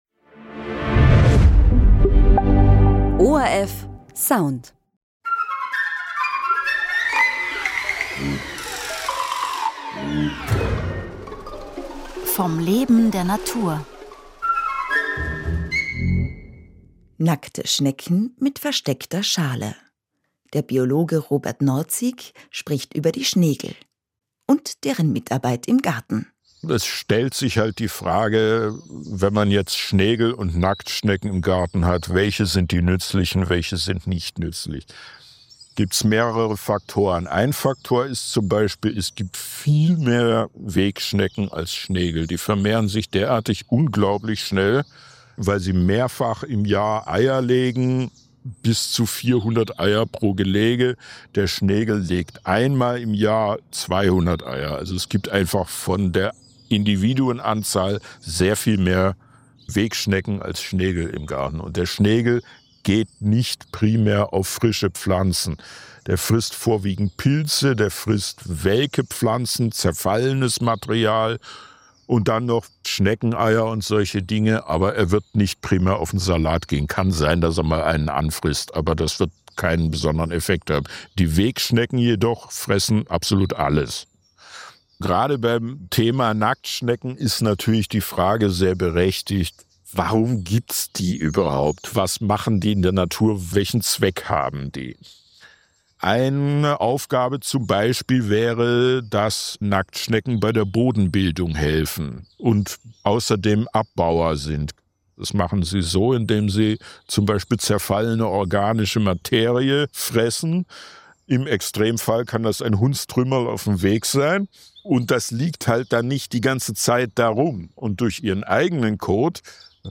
Radiointerview im ORF1 Radio